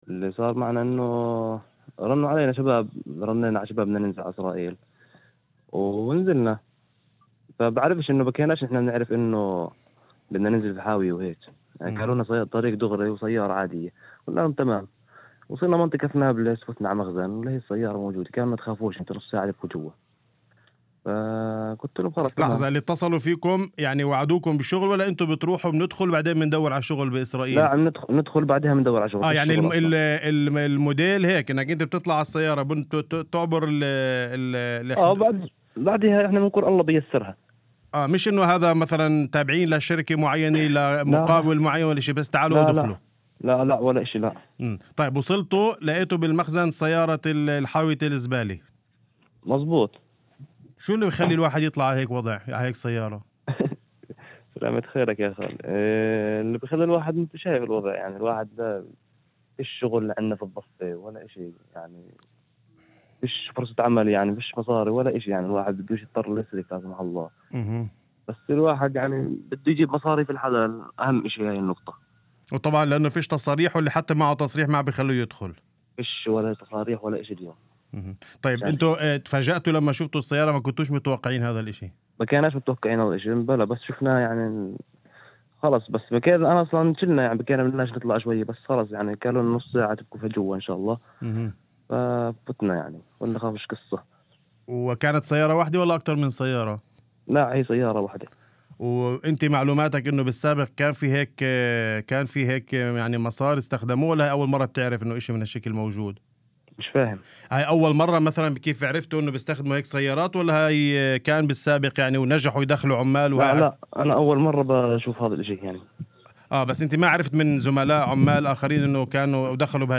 في مداخلة هاتفية ضمن برنامج "الظهيرة حتى الآن" على إذاعة الشمس